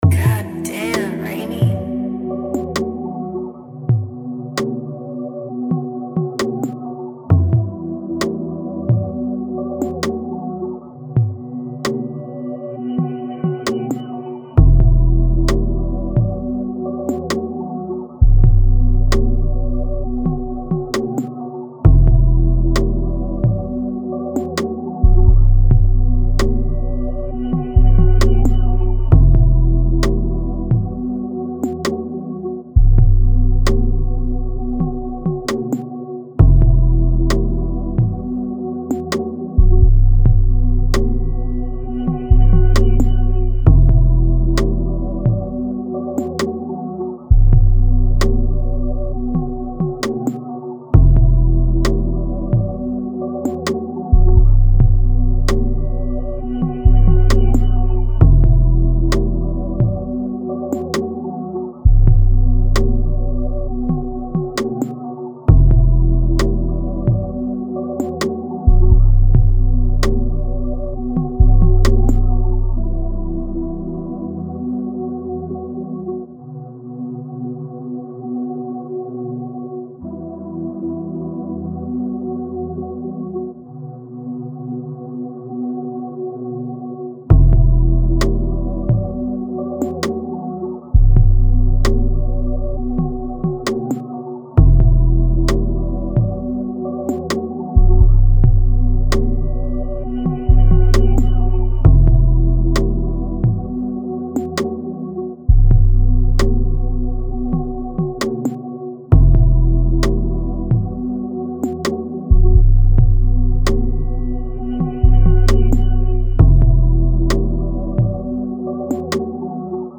120BPM (RNB/GUITAR/ALTERNATIVE RAP) CO